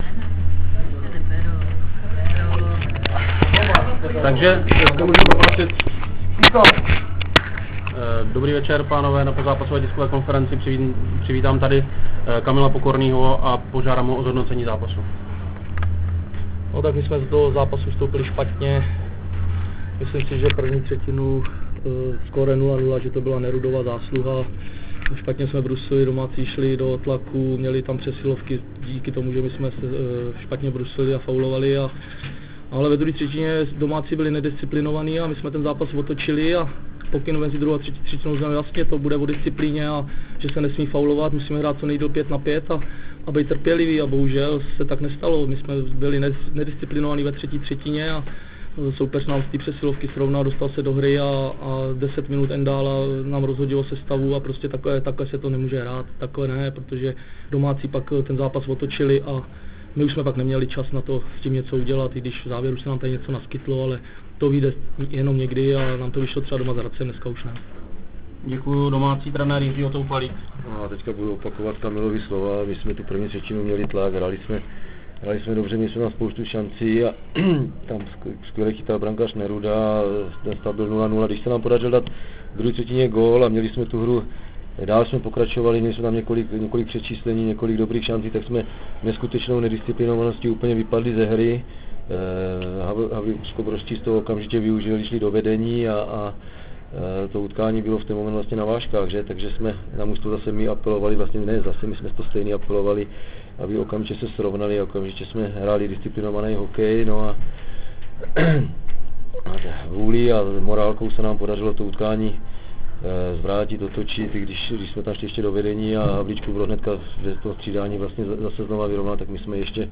Tisková Konference
Ohlasy trenérů
Tisková_Konference_44.WAV